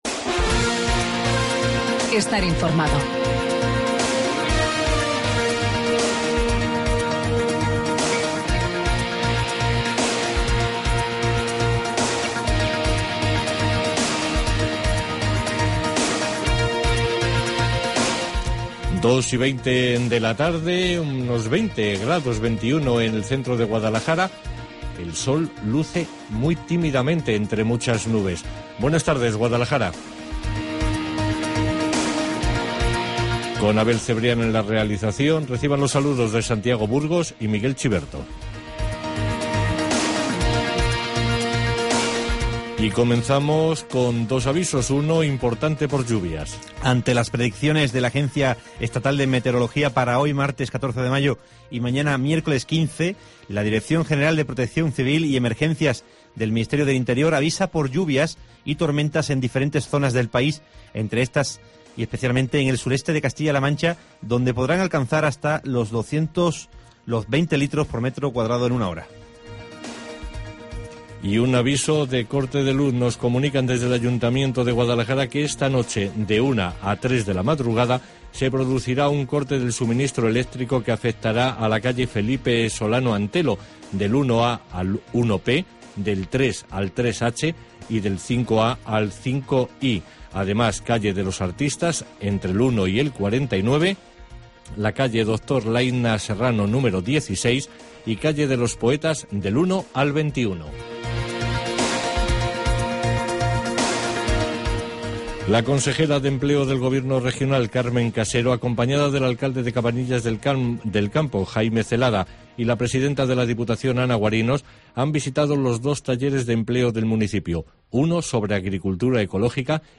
Informativo Guadalajara 14 DE MAYO